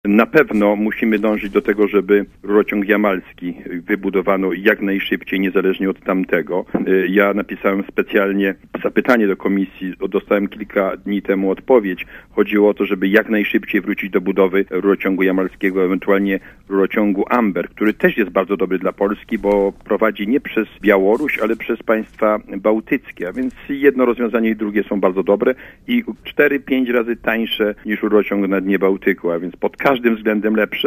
Mówi Jerzy Buzek, gość Radia ZET